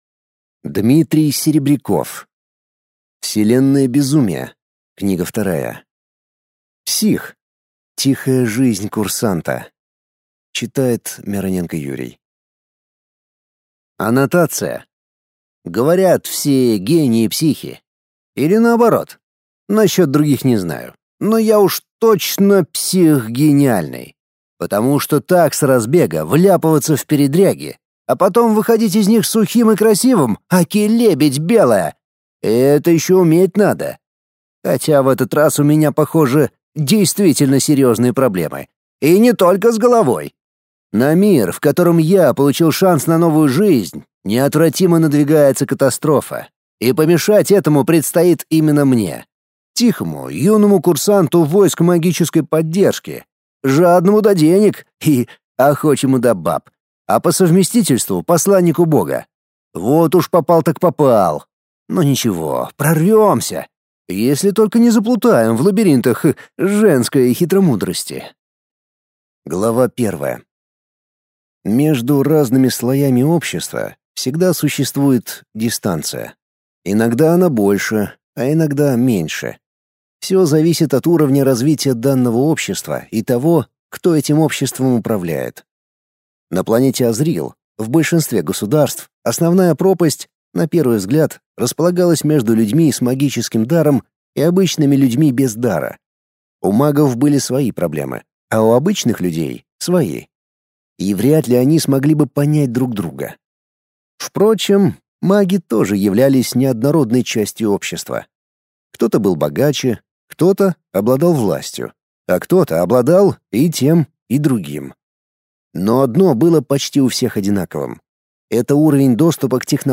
Аудиокнига Псих. Тихая жизнь курсанта | Библиотека аудиокниг